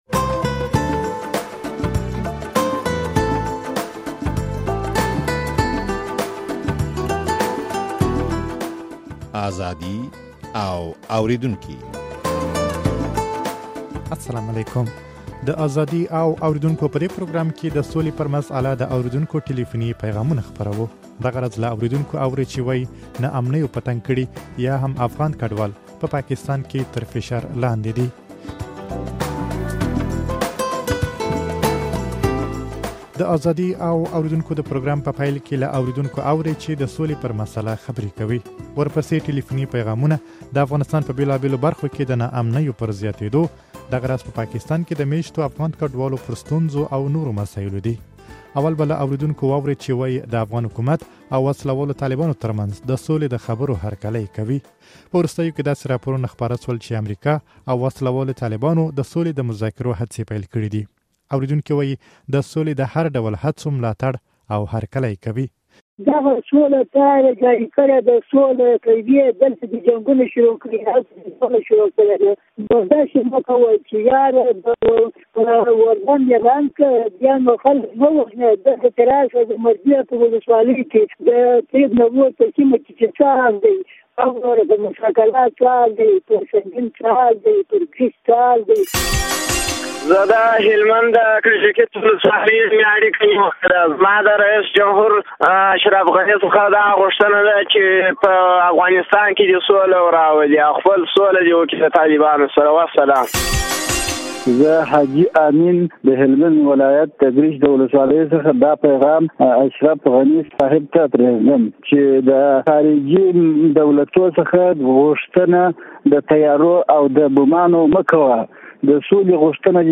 د ازادي او اورېدونکو په دې پروګرام کې د سولې پر مسئله د اورېدونکو ټليفوني پيغامونه خپروو